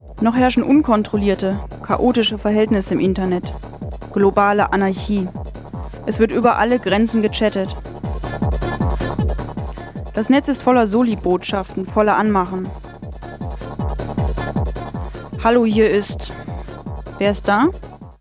Aus demTagesinfo und Morgenradio vom Montag, den 15.12. und Dienstag den 19.12.1995. Im Rahmen einer Ausstellung werden die Möglichkeiten und Inhalte des Mediums "Internet" mit Techno-Trance-Bässen untermauert.